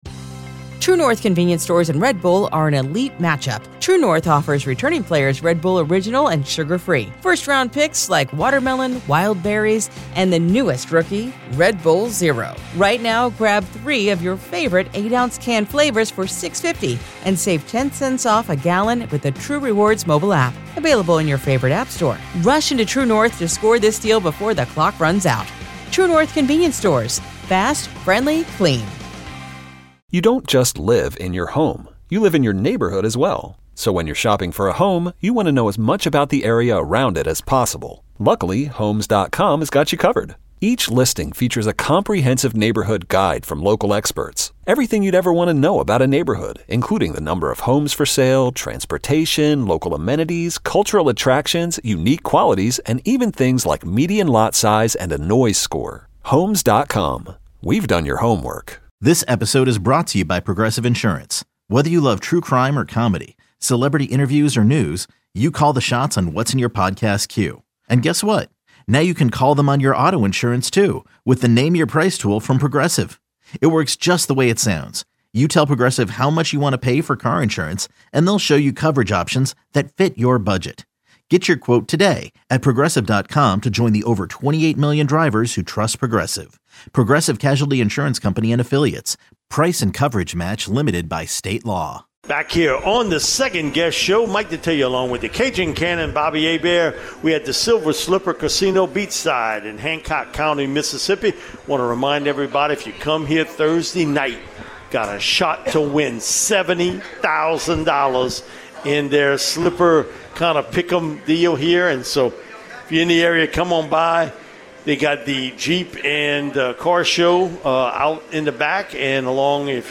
LSU interviews, press conferences and more